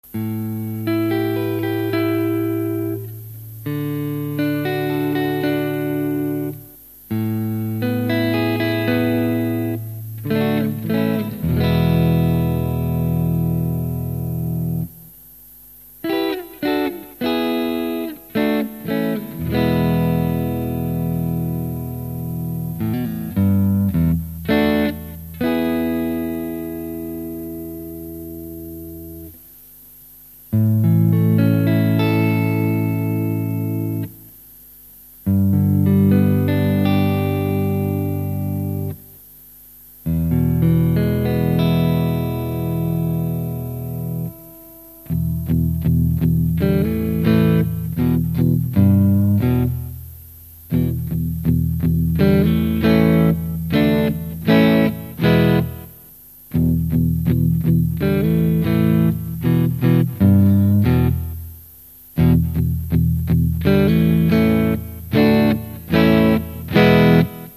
It's a classic rocking pickup. The neck is fruity and creamy, retaining enough clarity for good clean tone but fat enough for great overdrives. The bridge pickup offers extra hot vintage sounds.
These have a strong upper mid emphasis that combined with a Marshall is the only way to get those baseball bat in the face tones.
Listen here:     Bridge Full      Bridge Tapped        Neck Full